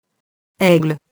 aigle [ɛgl]